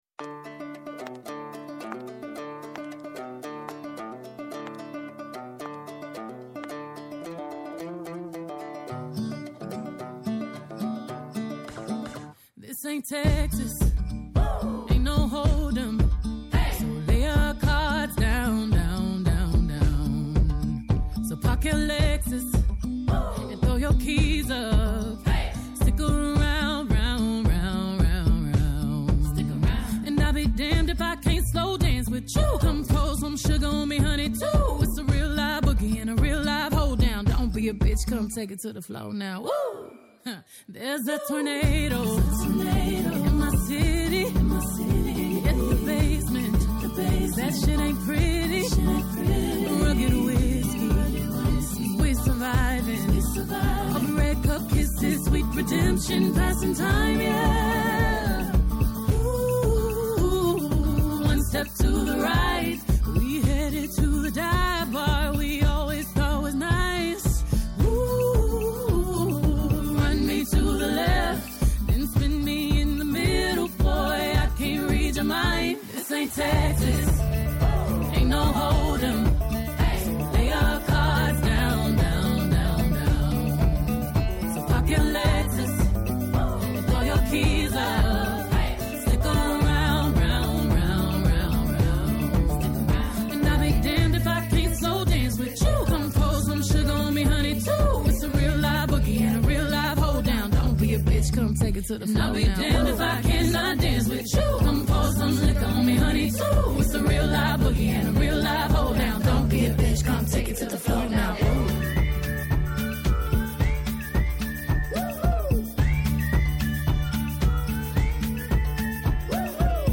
-O Γιάννης Πανούσης, καθηγητής Εγκληματολογίας του Πανεπιστημίου Αθηνών, πρώην υπουργός